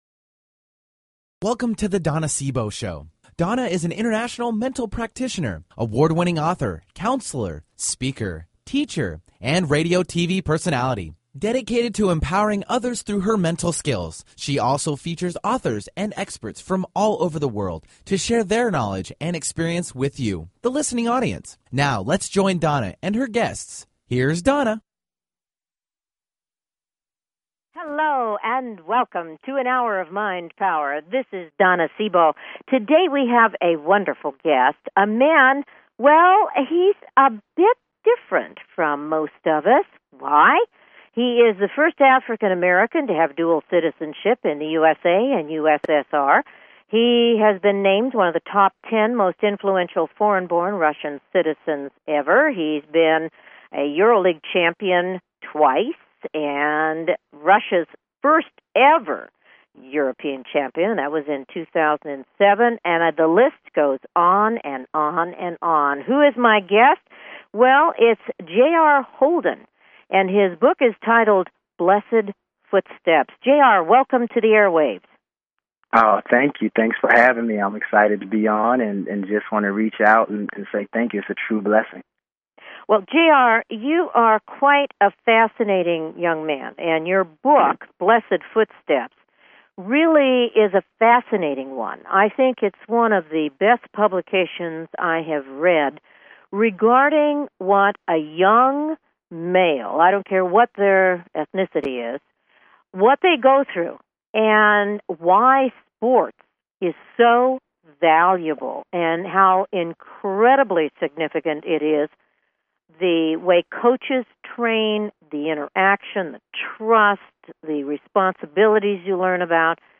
Her interviews embody a golden voice that shines with passion, purpose, sincerity and humor.
Callers are welcome to call in for a live on air psychic reading during the second half hour of each show.